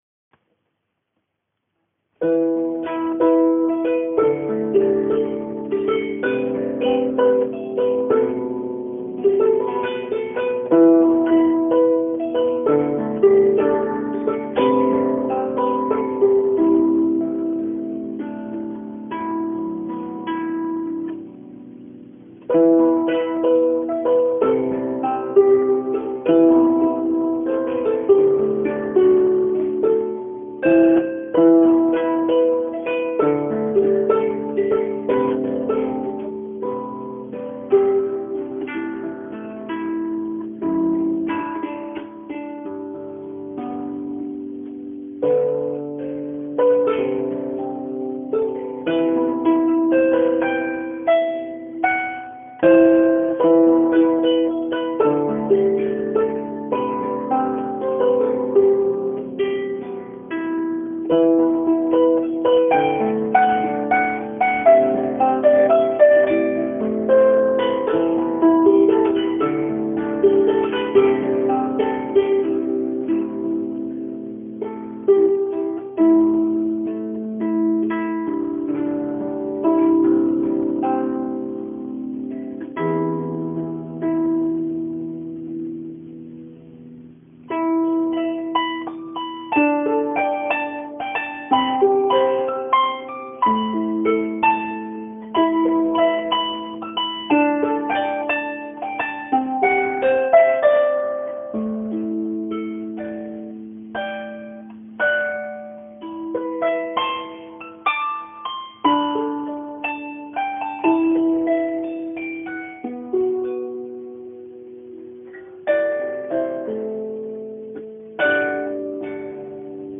a composé spécialement pour nous un morceau de harpe, intitulé « On the trip ».